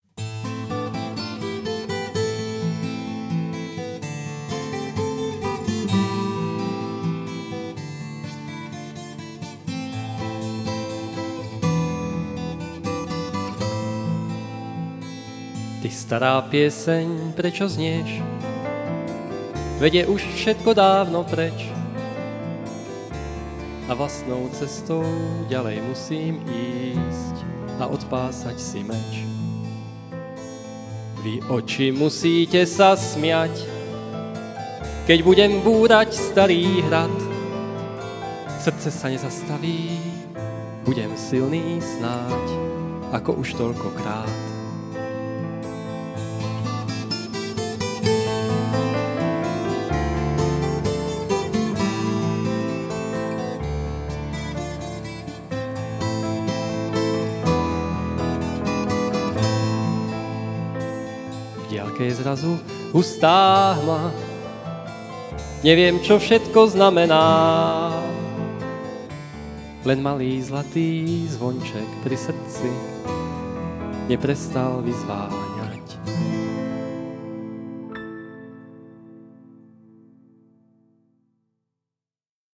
Niektoré zverejnené nahrávky piesní sú pracovné (a historické) a slúžia na správne uchopenie autorovho zámeru.